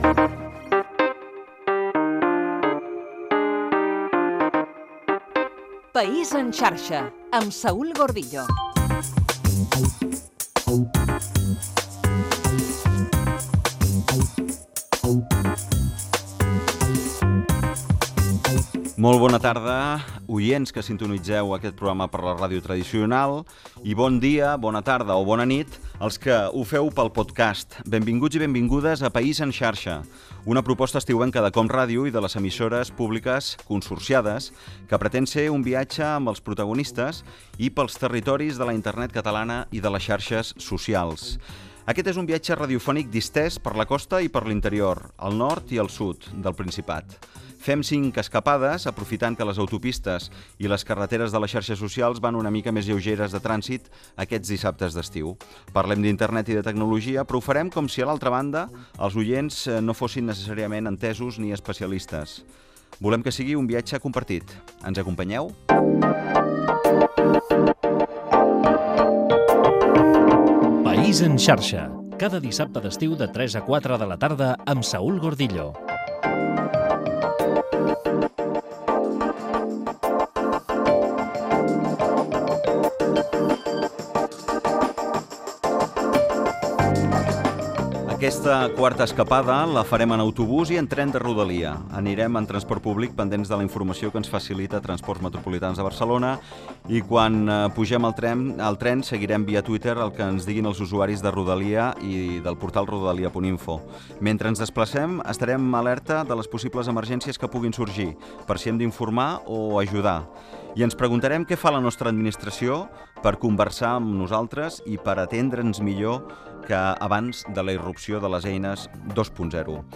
Careta, salutació del programa d'estiu sobre Internet catalana i les xarxes socials.
Divulgació
FM